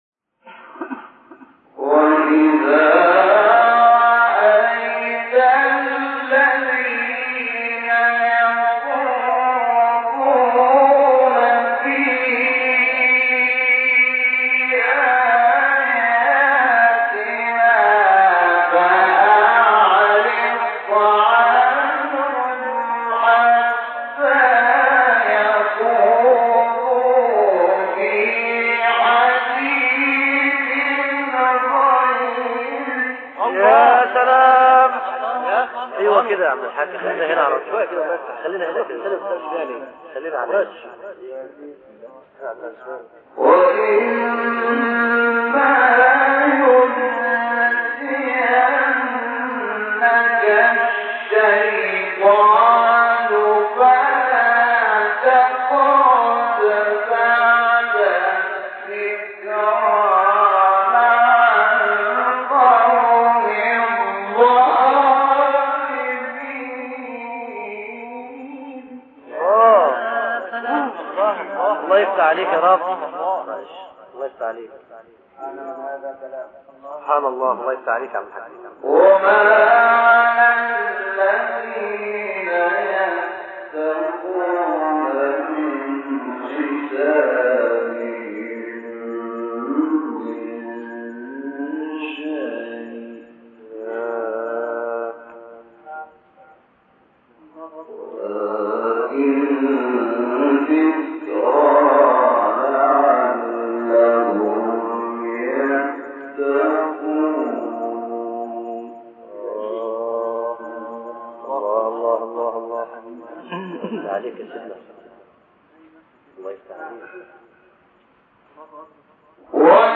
مقطع انعام استاد شحات در مصر | نغمات قرآن | دانلود تلاوت قرآن